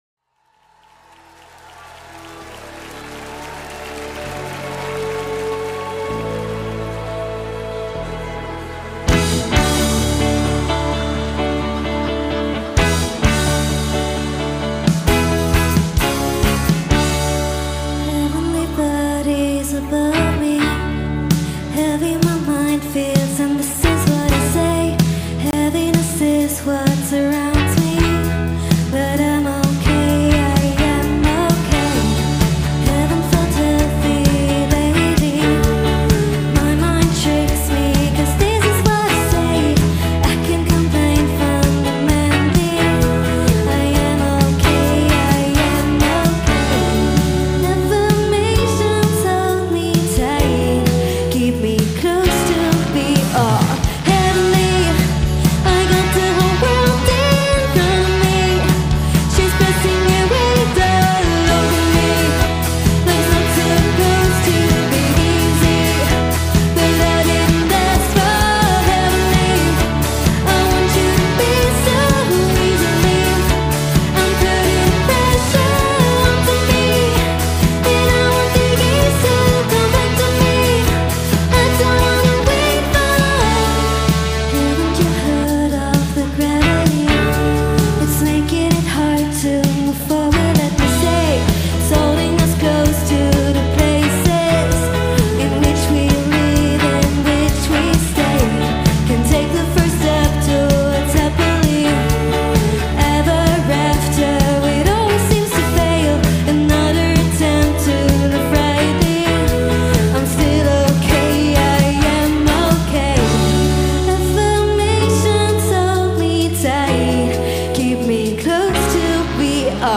live from The Palladium in Cologne, Germany
German Indie